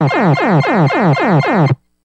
Video Game Synth You Won Sound